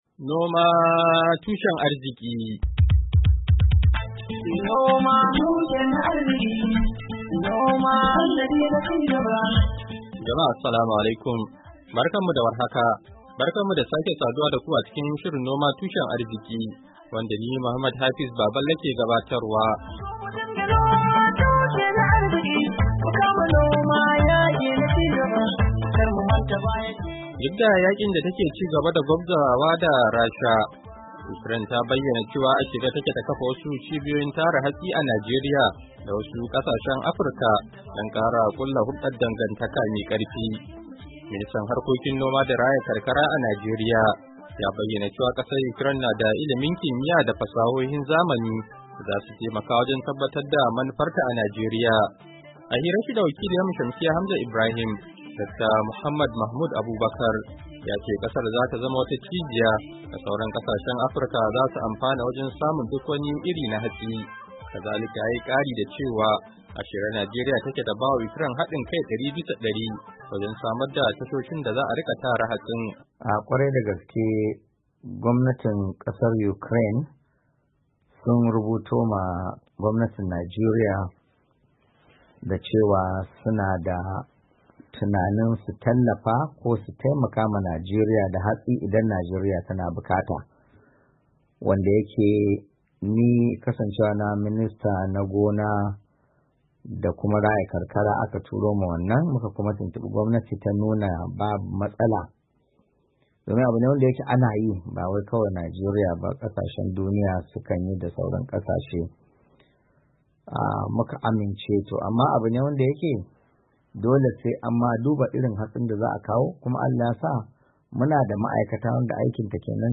Shirin na wannan makon ya tattauna da Ministan Harkokin Noma da Raya Karkara a Najeriya, Dakta Mohammad Mahmud Abubakar, kan tayin da kasar Ukraine ta yiwa Najeriya na tallafa mata da hatsi da kuma kafa cibiyoyin hatsi a kasar.